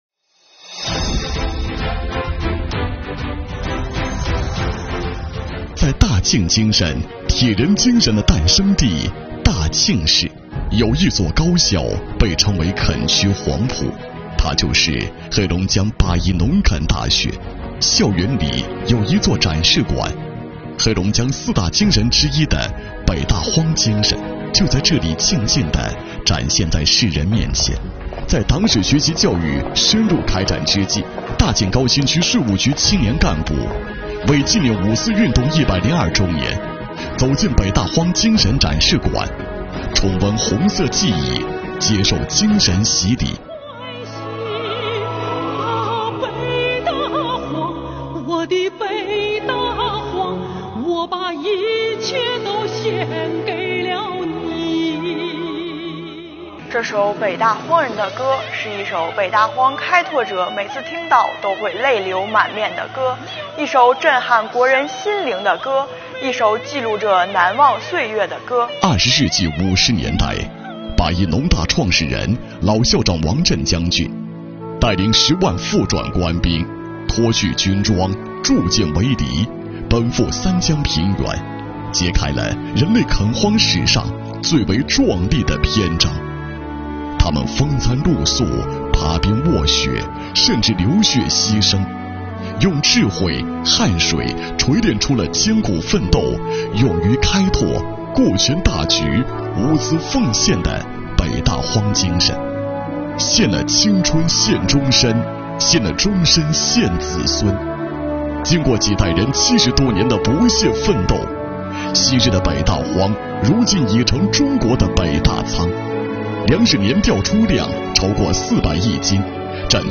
在党史学习教育深入开展之际，让我们跟随黑龙江税务干部走进“北大荒精神展示馆”，聆听“北大荒”故事，重温红色记忆，接受精神洗礼。